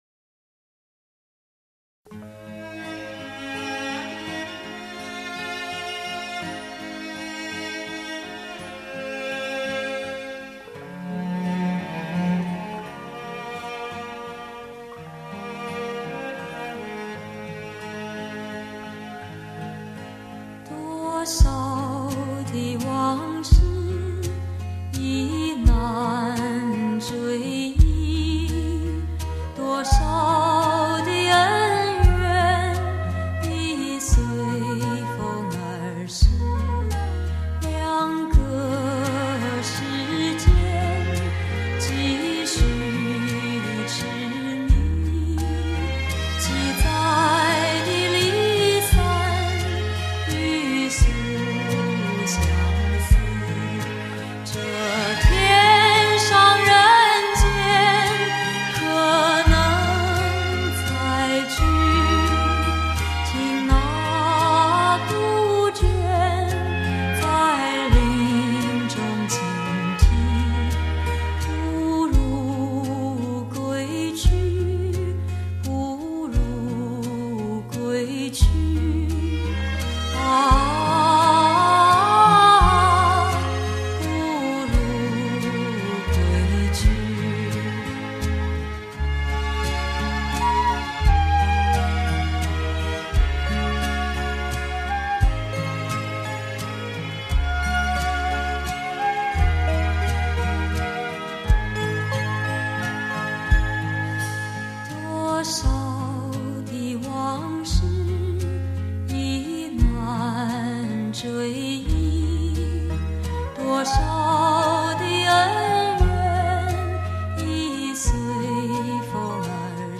她的声音豪无造作，独具的天赋和努力使她与音乐融合一体，她是一位完美得天衣无缝的天使歌者，